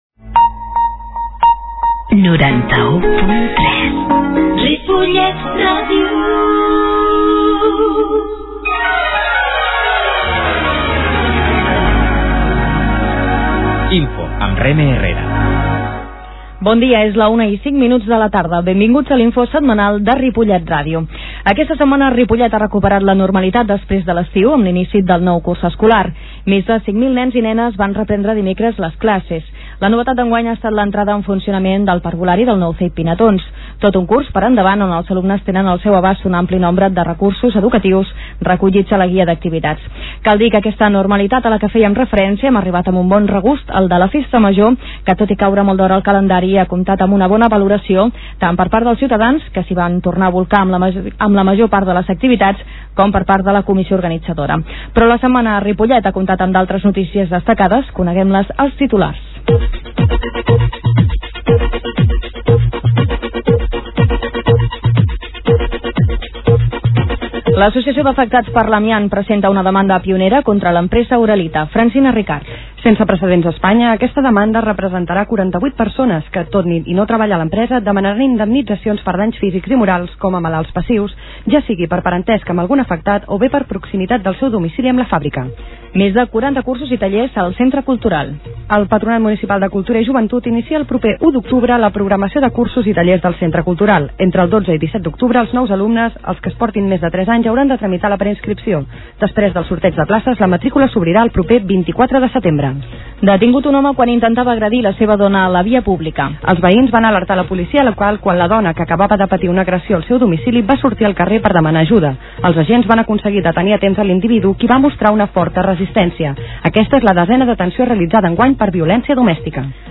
Comunicació Ripollet Ràdio posa en marxa un informatiu setmanal -Comunicació- 13/09/2007 L'emissora municipal va posar en marxa el divendres 14 de setembre a les 12 hores el noticiari local Info. El programa, d'una hora de durada, repassa l'actualitat de la setmana.